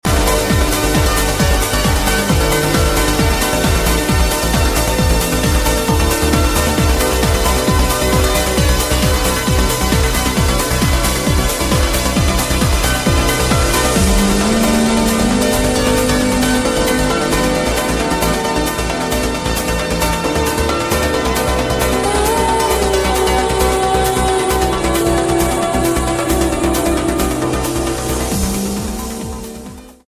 Dance & Trance